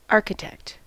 Ääntäminen
Vaihtoehtoiset kirjoitusmuodot (rikkinäinen englanti) harchitect Ääntäminen US UK : IPA : /ˈɑːkɪtɛkt/ US : IPA : /ˈɑɹkɪtɛkt/ Lyhenteet ja supistumat arch.